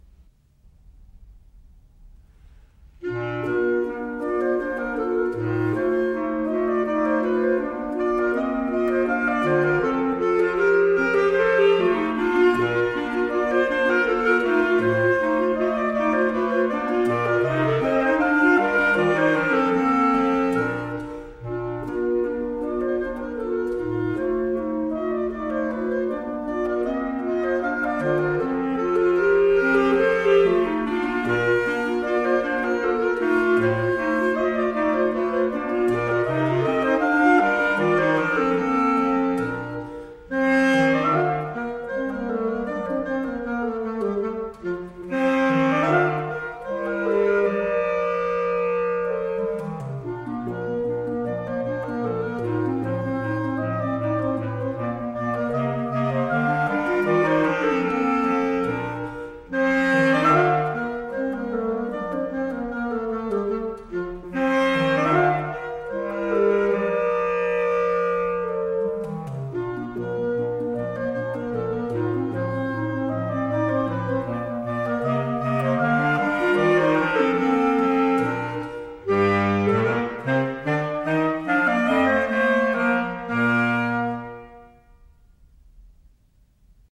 Polonaise
B flat